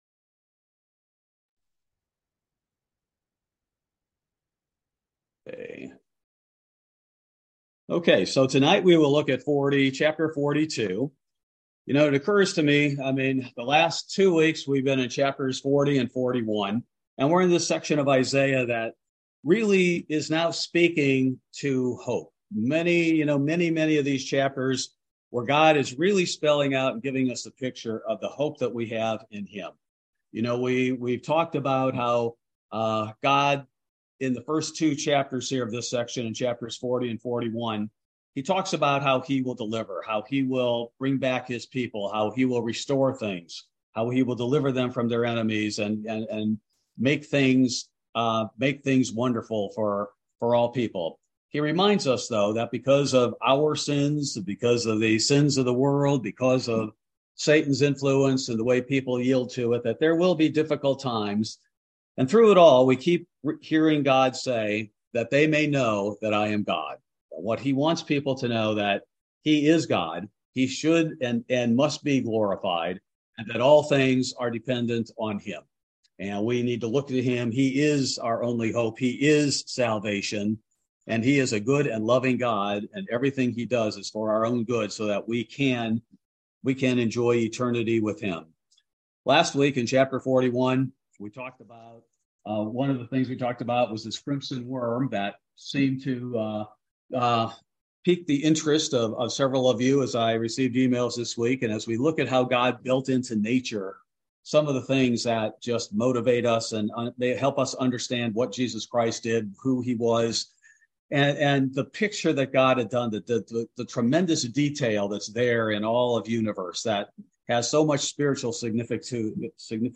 This verse by verse Bible study covers primarily Isaiah 42: Bruised Reeds and Smoking Flax; The First and Second Coming of Christ